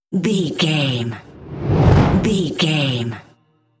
Dramatic whoosh to hit trailer
Sound Effects
Atonal
dark
intense
tension
woosh to hit